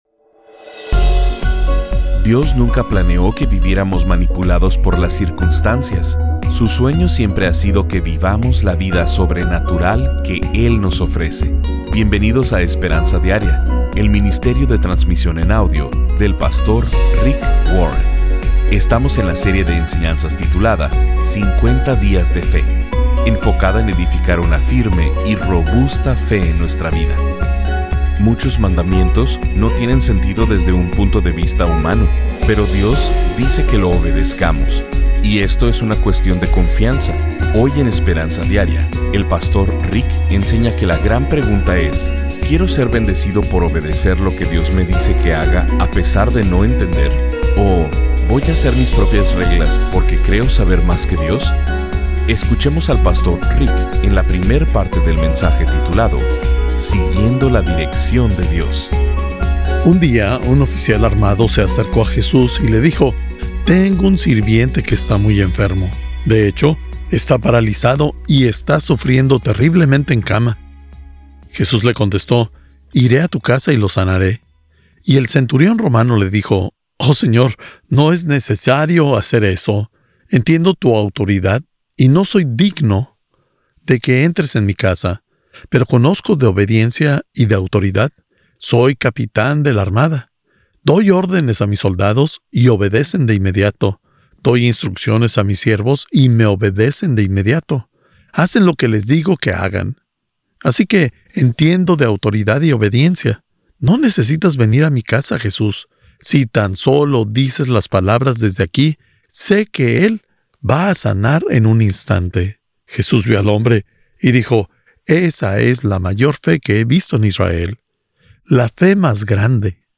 1 Siguiendo la Dirección de Dios — Parte 1 Play Pause 1d ago Play Pause เล่นในภายหลัง เล่นในภายหลัง ลิสต์ ถูกใจ ที่ถูกใจแล้ว — El Pastor Rick enseña la importancia de obedecer a Dios completamente porque la obediencia parcial sigue siendo desobediencia. Este mensaje te dará una idea de las normas inmutables de Dios y su perspectiva general, y te ayudará a confiar en Él aun cuando no entiendas Su plan.